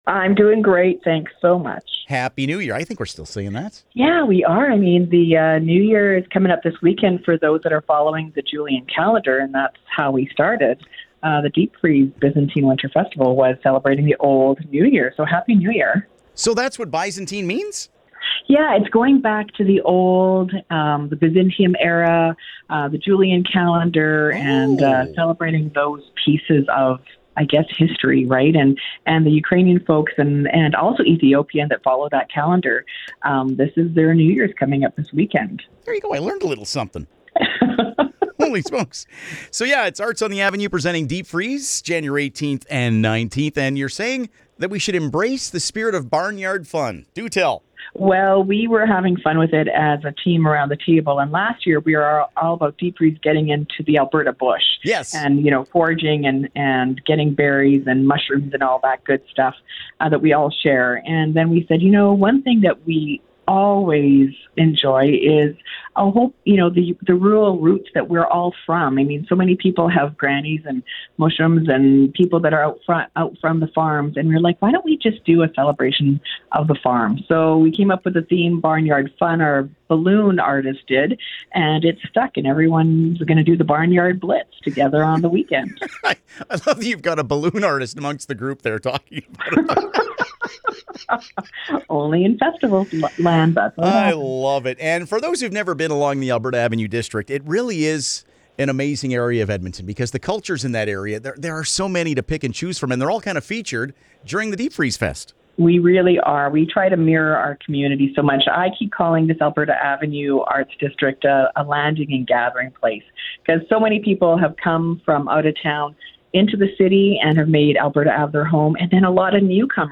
deep-freeze-fest.mp3